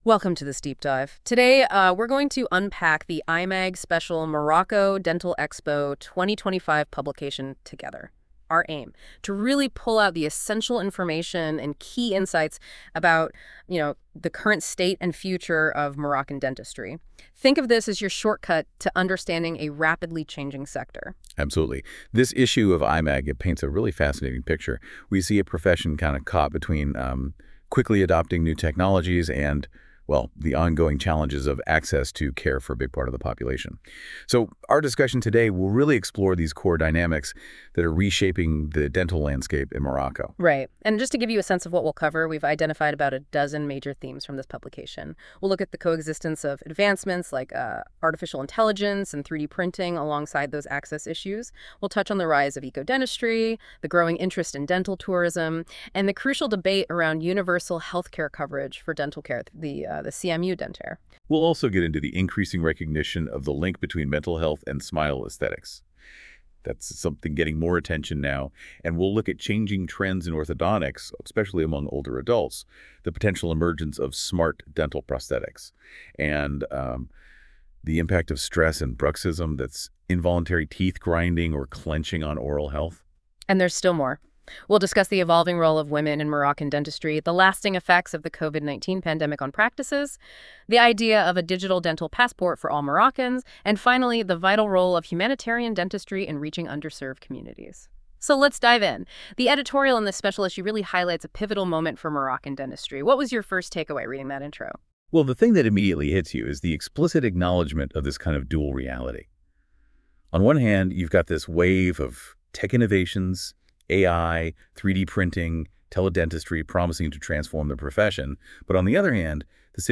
Les chroniqueurs de la Web Radio R212 ont lus attentivement le magazine de L'ODJ Média et ils en ont débattu dans ce podcast